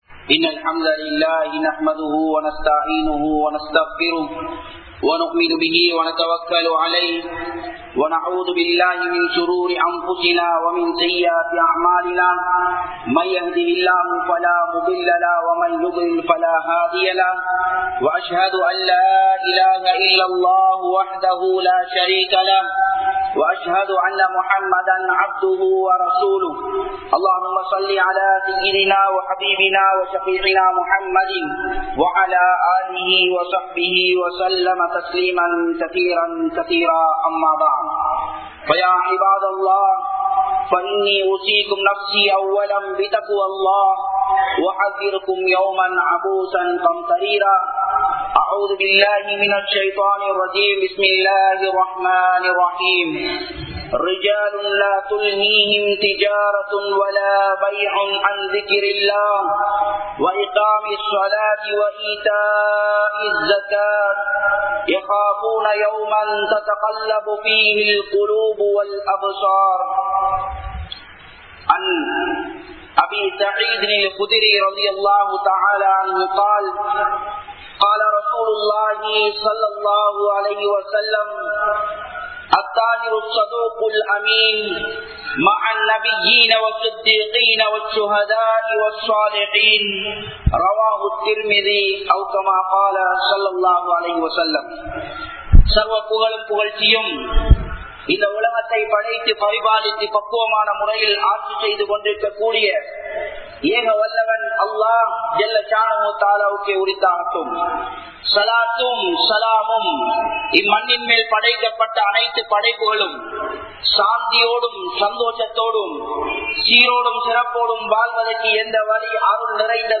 Unmaiyaana Muslim Viyaapari Yaar?(உண்மையான முஸ்லீம் வியாபாரி யார்?) | Audio Bayans | All Ceylon Muslim Youth Community | Addalaichenai
Grand Jumua Masjidh